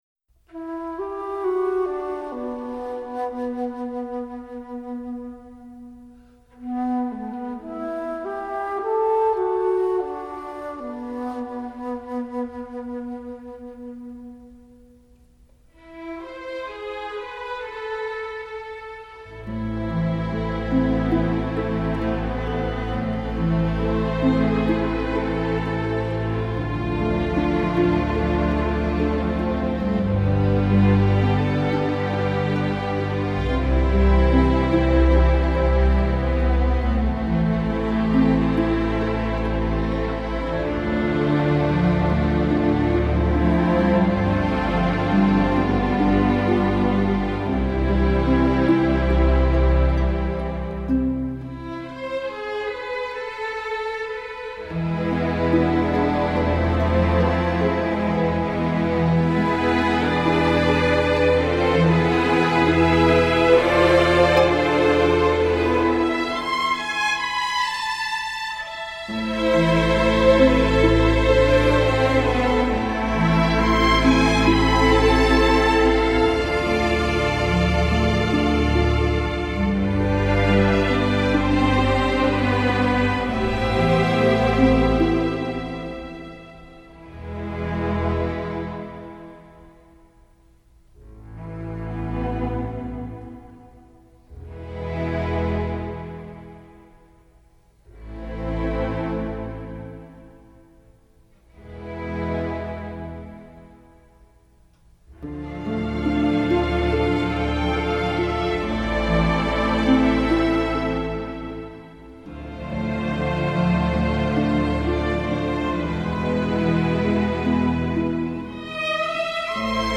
类别:电影原声
仍以温和的弦乐为主
曲终前使用排笛，以自然的气音塑造出乡野气氛。